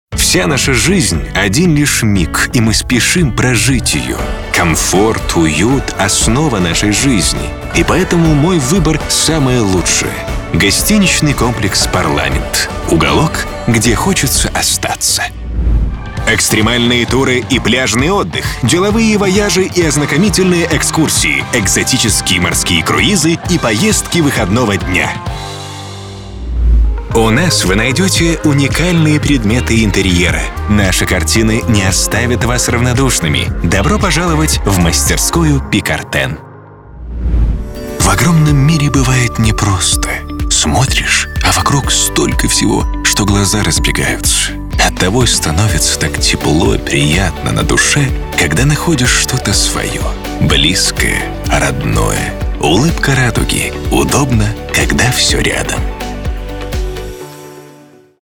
В моем арсенале насыщенный и неповторимый тембр, который будет незаменим для воплощения ваших творческих маркетинговых идей, а также недюжинный опыт в сфере озвучивания в таких компаниях как MirCli, Сбербанк, Valvoline, Bettersize, SkyWay и многих других.